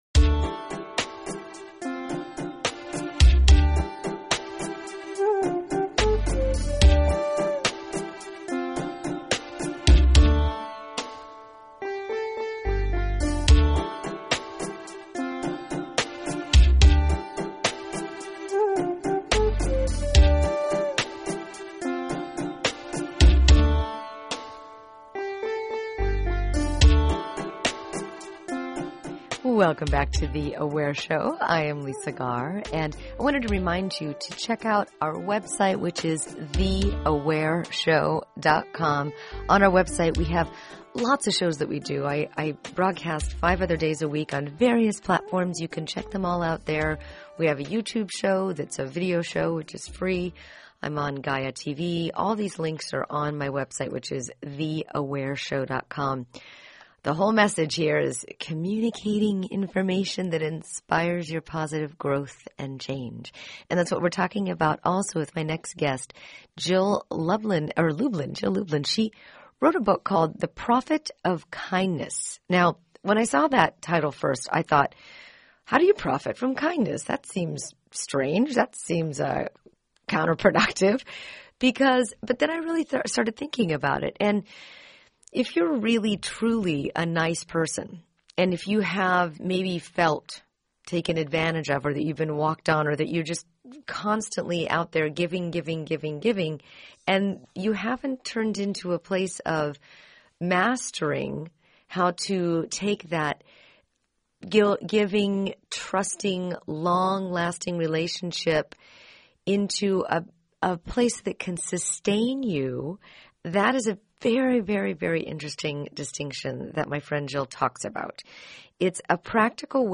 2017 on KPFK http